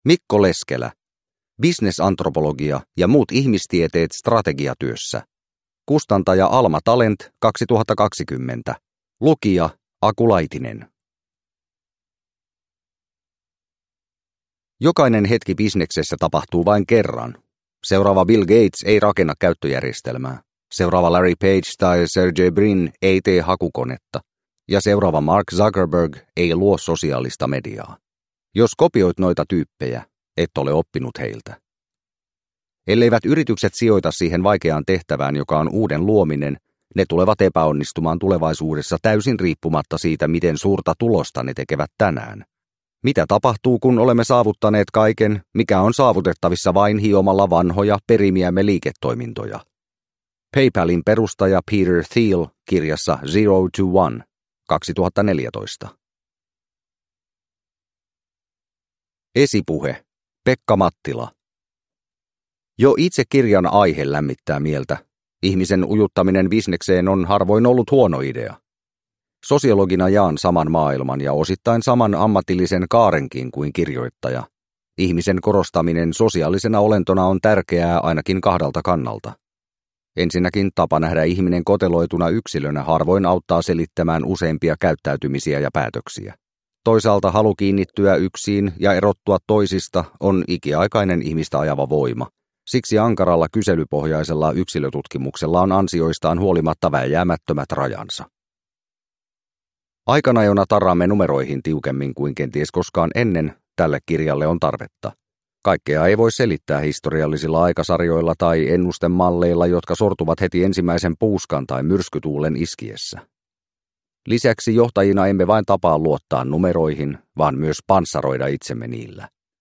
Bisnesantropologia – Ljudbok – Laddas ner